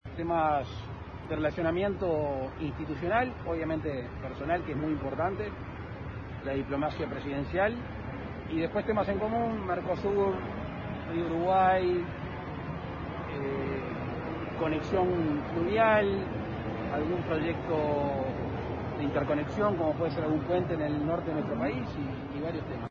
A la salida de la Residencia de Olivos, Lacalle Pou dialogó con los medios  Hoy y la Red 92, respecto a  la reunión dijo que “se trataron temas de relacionamiento institucional y personal, Mercosur, Río Uruguay y algún proyecto de interconexión”.